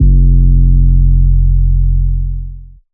808 2 {F} [ Count ].wav